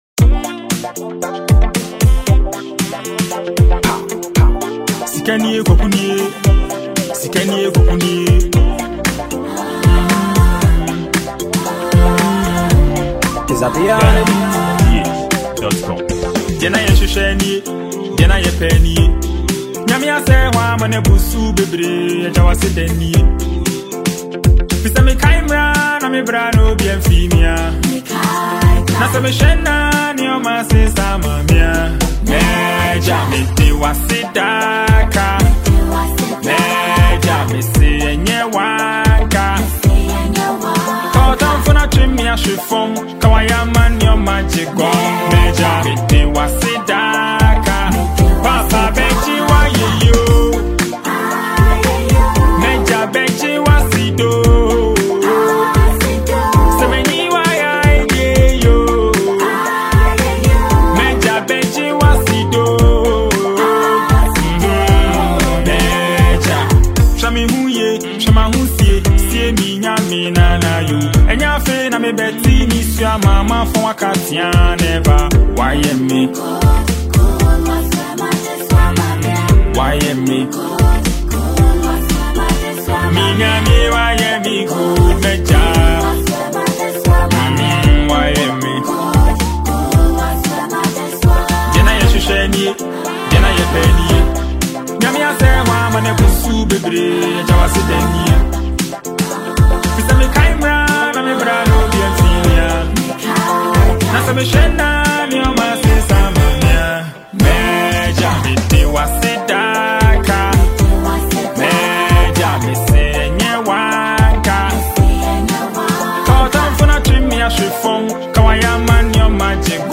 Ghanaian highlife singer and songwriter